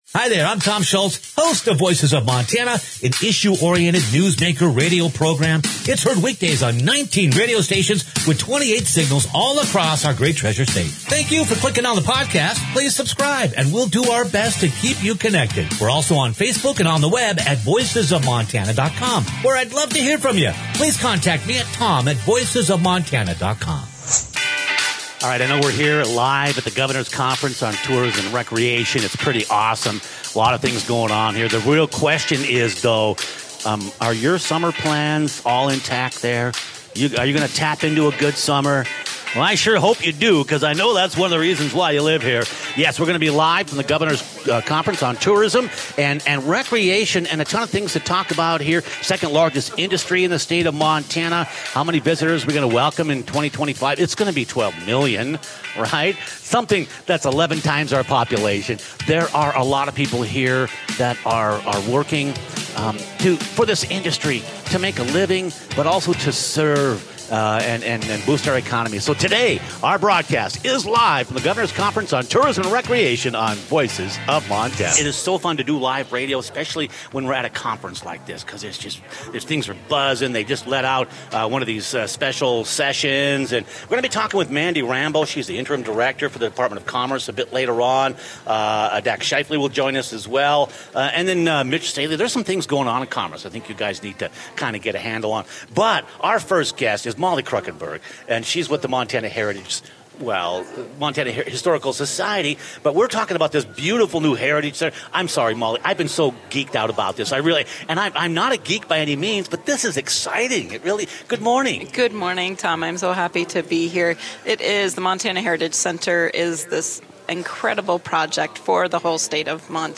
Montana Tourism – Live from the Governor’s Conference - Voices of Montana
Those question are at the heart of every networking conversation at the annual Governor’s Conference on Tourism. Click on the podcast for the live broadcast and stay connected with the trends and anticipations of recreation and tourism